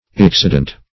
Search Result for " excedent" : The Collaborative International Dictionary of English v.0.48: Excedent \Ex*ced"ent\, n. [L. excedens, -entis, p. pr. of excedere.